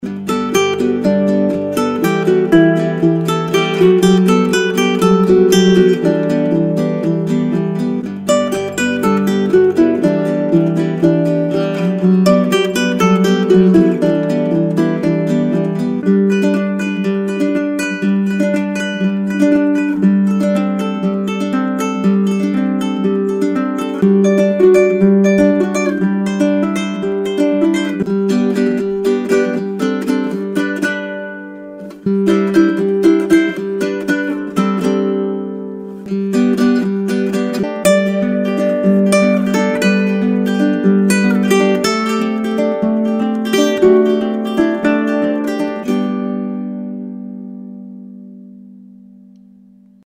A collection of bright and chirpy ukulele rhythms at 120 BPM, perfect for chill out, jazz, pop and for adding a little organic texture to your tracks.
Ukulele-Grooves-1-Demo.mp3